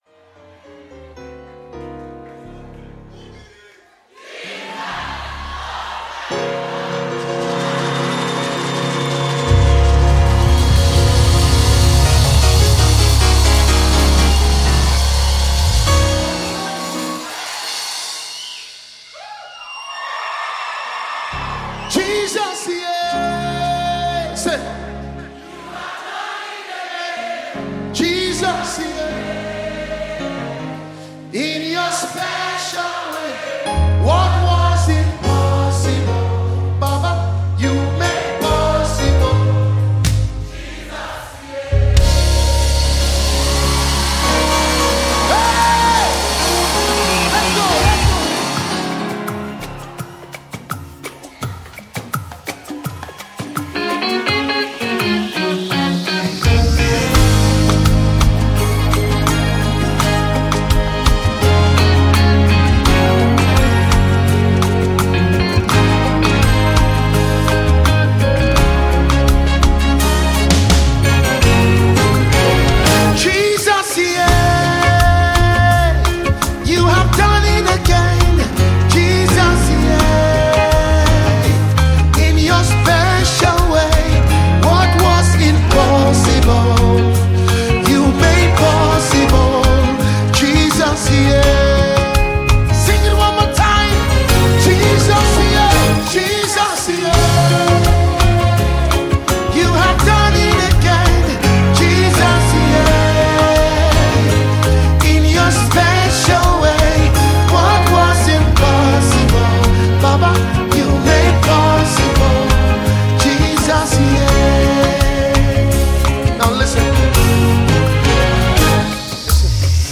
Nigerian gospel sensation